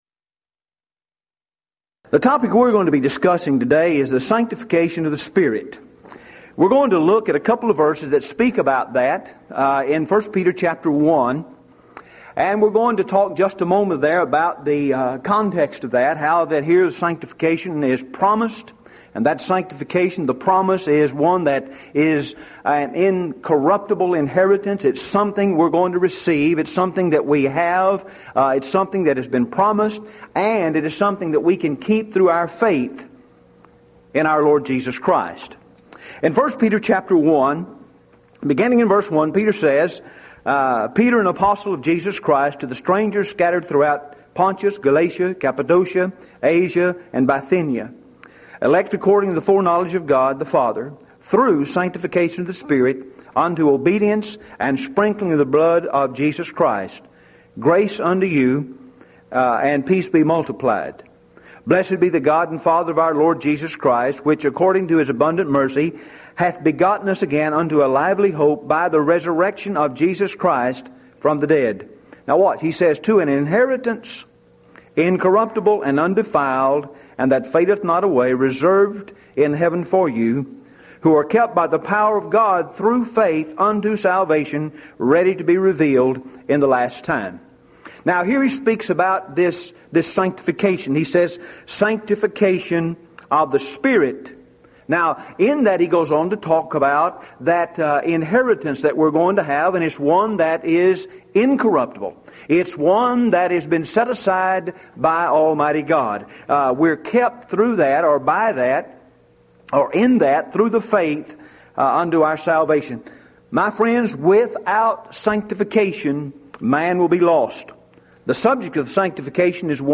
Event: 1997 Mid-West Lectures
lecture